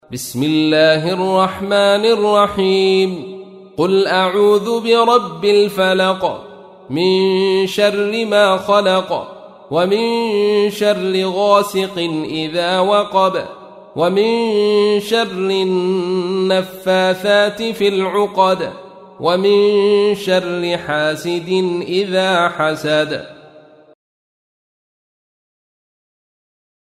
تحميل : 113. سورة الفلق / القارئ عبد الرشيد صوفي / القرآن الكريم / موقع يا حسين